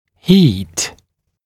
[hiːt][хи:т]жара, тепло, накал, высокая температура